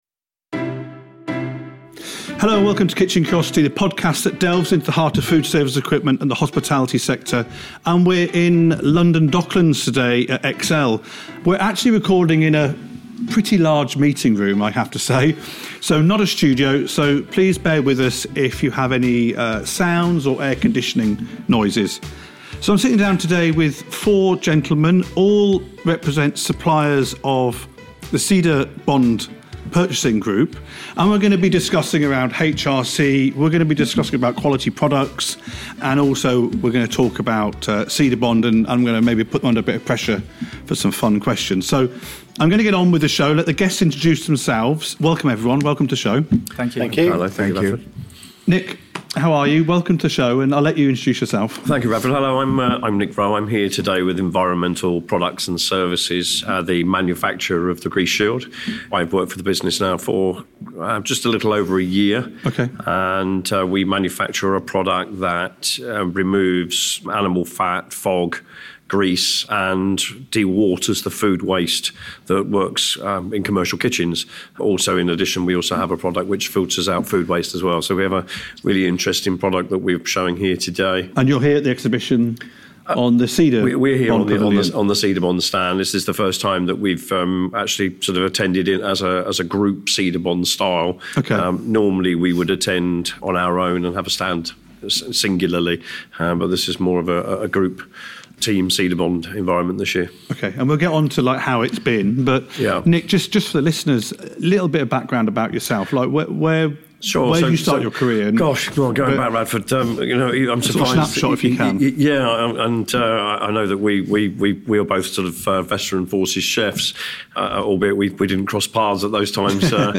This episode comes from HRC at Excel London with four members of Cedabond, representing some of the most respected and established distributors in the UK foodservice equipment industry. They will be discussing HRC, the importance of high quality products and Cedabond itself.